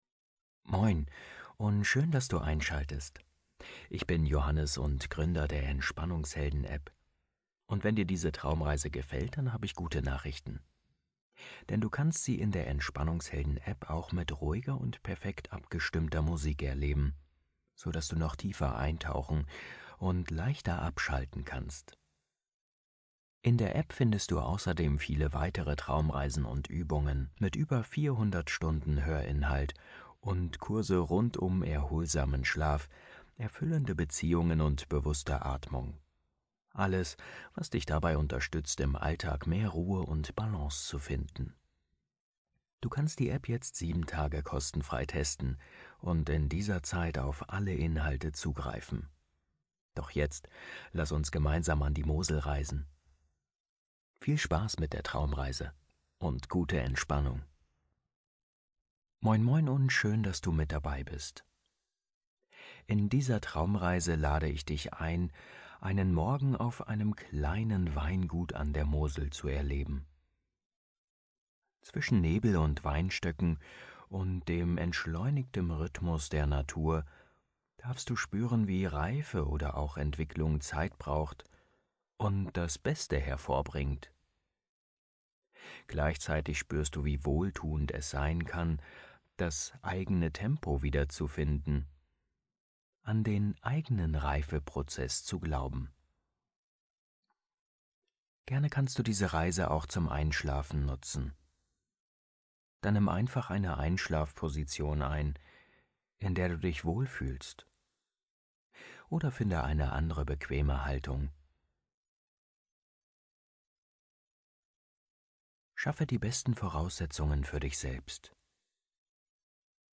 Traumreise - Weingut an der Mosel ~ Entspannungshelden - einschlafen, meditieren, entspannen Podcast